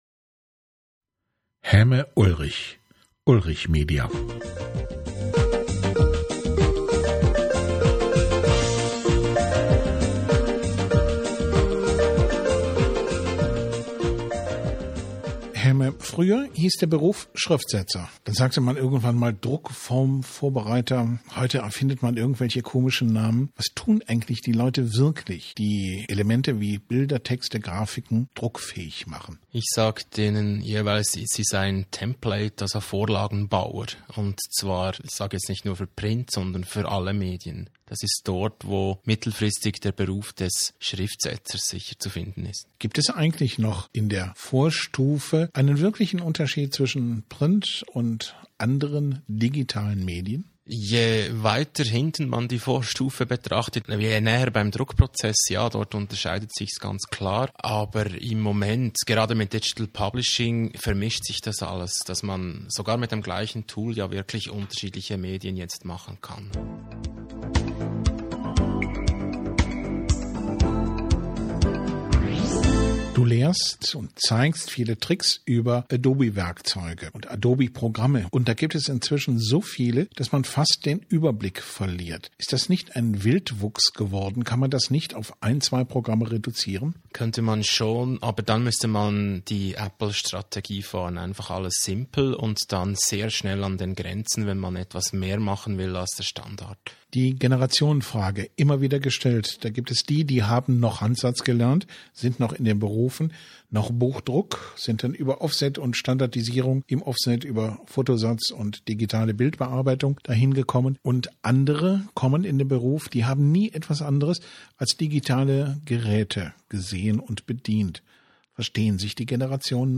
Interview, ca. 5 Minuten